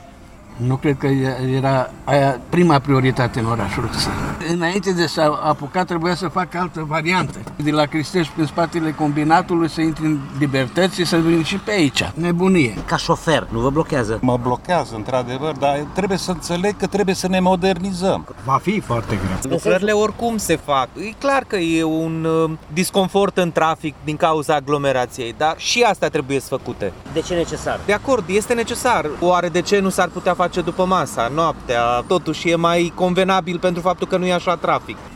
Șoferii sunt conștienți că vor fi blocaje suplimentare în trafic, dar spun că reamenajarea străzii Gh. Doja era necesară: